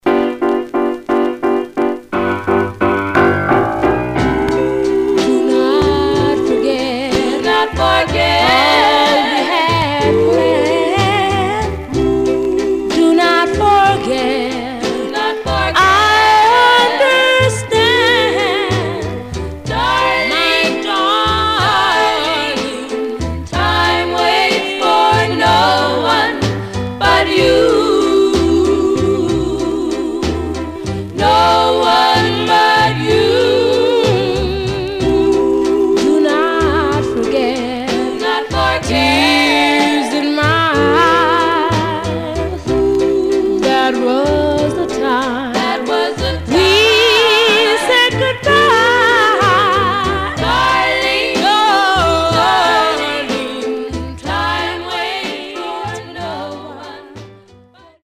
Some surface noise/wear
Mono
Black Female Group